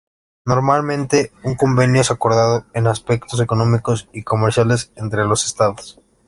a‧cor‧da‧do
/akoɾˈdado/